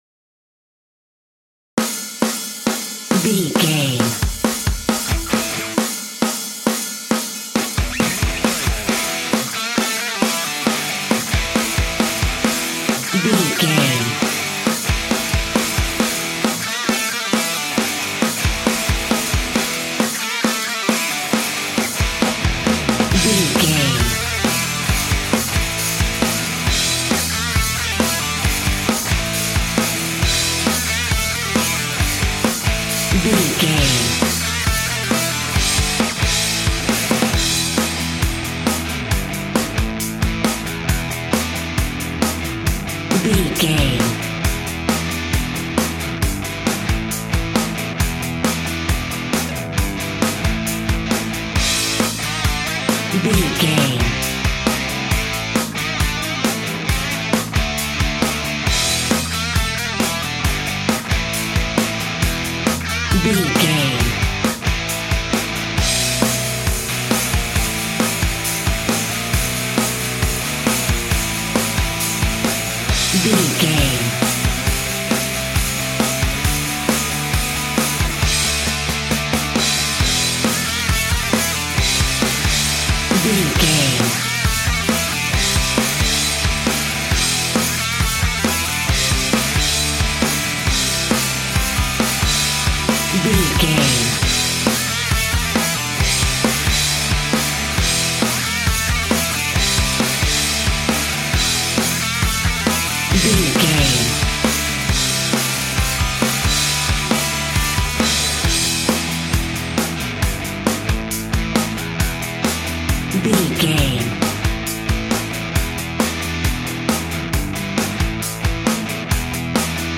Aeolian/Minor
hard rock
heavy metal
blues rock
distortion
rock instrumentals
rock guitars
Rock Bass
Rock Drums
heavy drums
distorted guitars
hammond organ